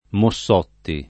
[ mo SS0 tti ]